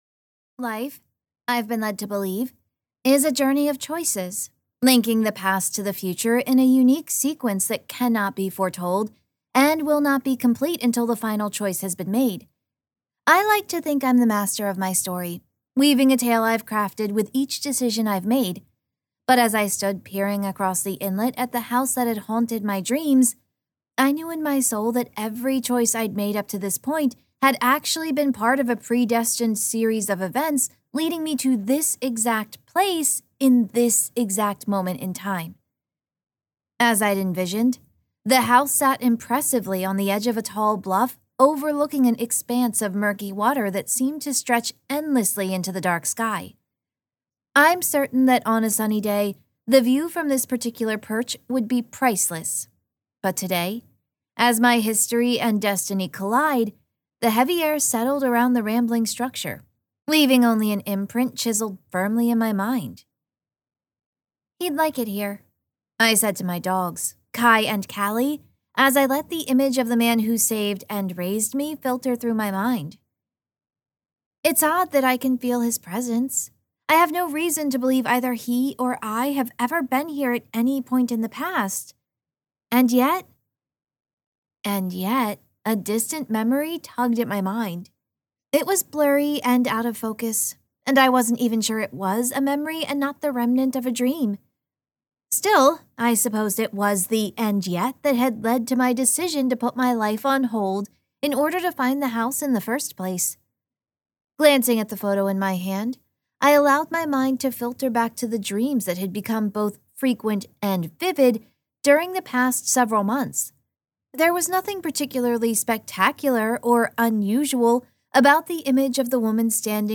Book 1 Retail Audio Sample Cottage on Gooseberry Bay Halloween Moon.mp3